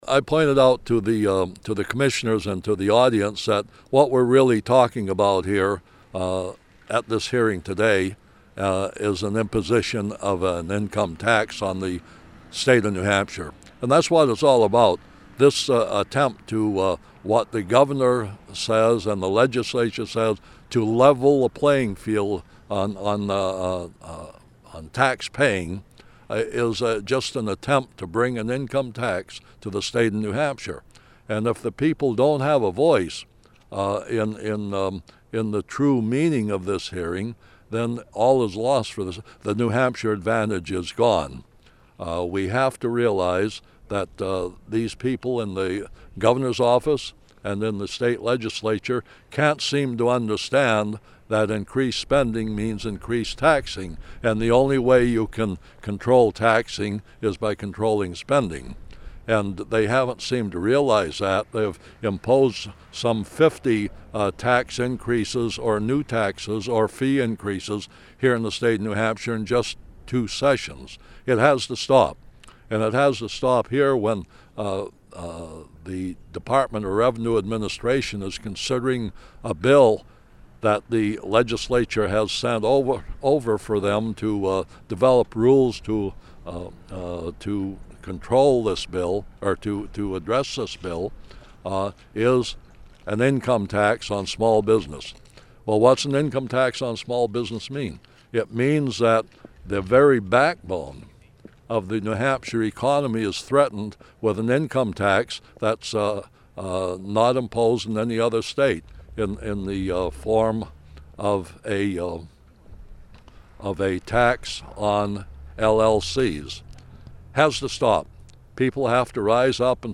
The following audio cuts are interviews I did 12/16/09 at the LLC Tax hearing in Concord and played on the show:
Interview- Former Sen. George Lovejoy